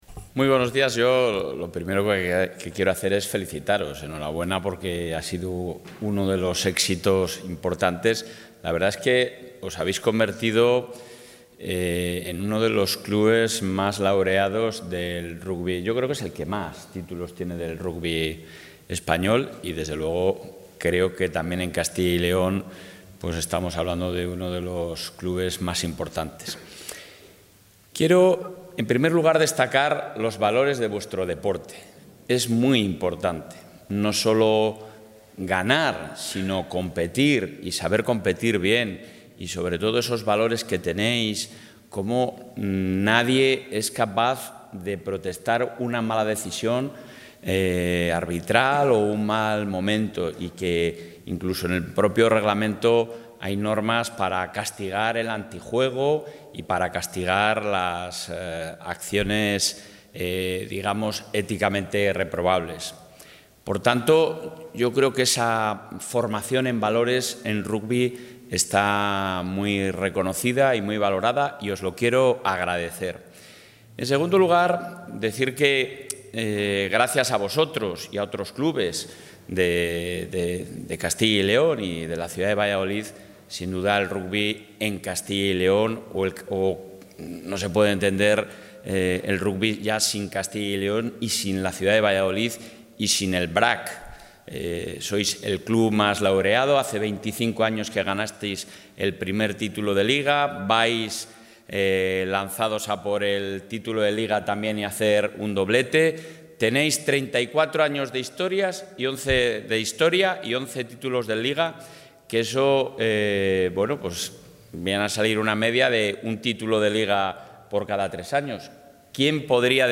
Intervención del presidente de la Junta.
El presidente de la Junta de Castilla y León, Alfonso Fernández Mañueco, ha recibido esta mañana, en la sede del Gobierno autonómico, al Valladolid Rugby Asociación Club Quesos Entrepinares, que el pasado domingo ganó la 90 edición de la Copa de S.M El Rey de Rugby, al que ha felicitado por su trabajo y tesón para lograr este título, que supone situar a Valladolid y a Castilla y León en el epicentro del deporte.